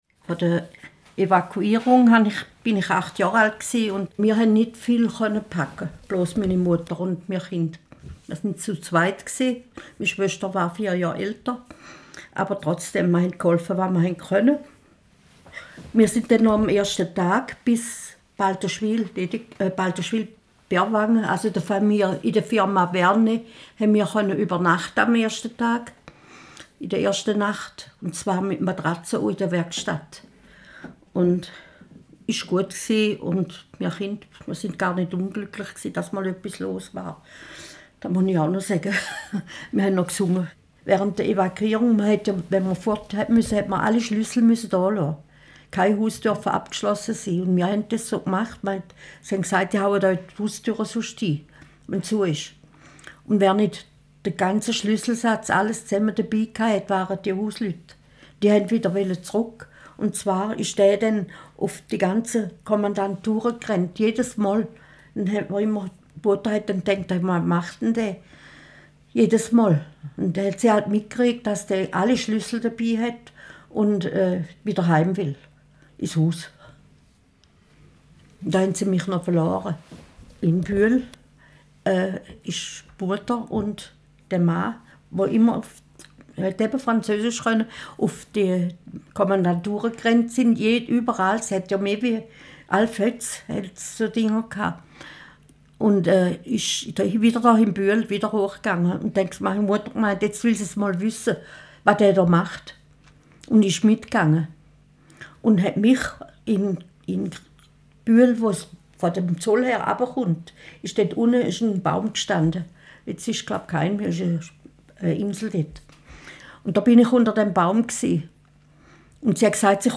Dazu sollen Zeitzeugen, die mit den Gebäuden in Verbindung stehen, zu Wort kommen. In Tonaufnahmen mit Hilfe der Methode «Oral History» sollen diese Menschen zu ausgewählten Bauwerken erzählen und ihre persönlichen Erinnerungen und Erlebnisse teilen.